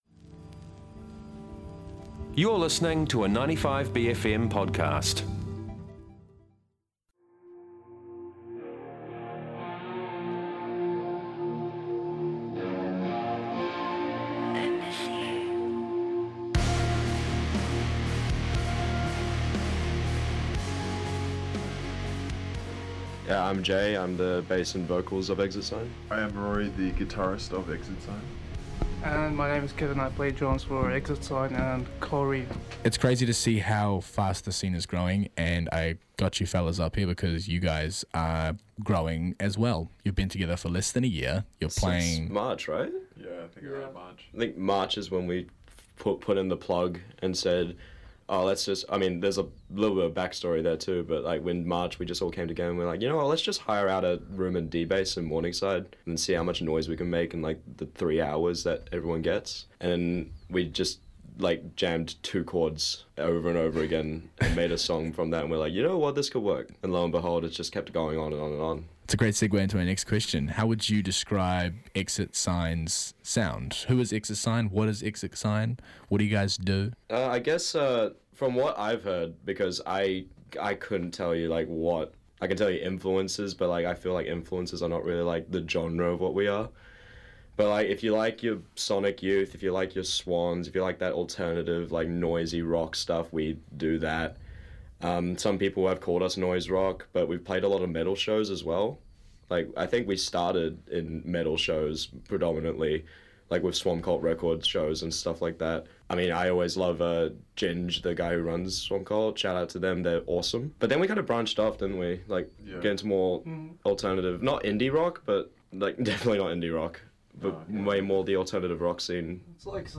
Exit Sign bring their psychedelic-metal stylings to the show this week for a chat about what's in the works, as well as how they came to be.